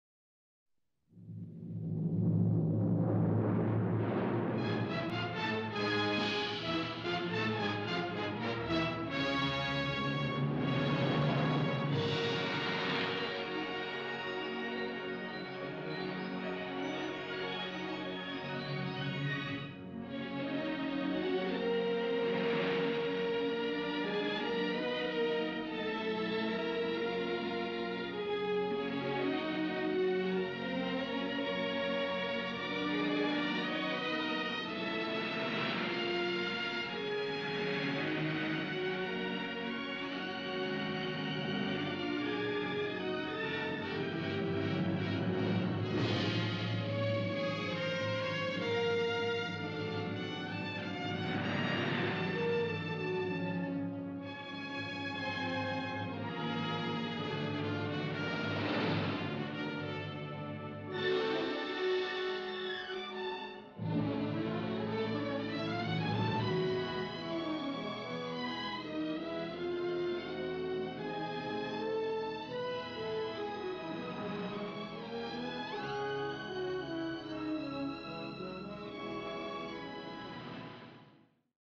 una banda sonora de ofuscador romanticismo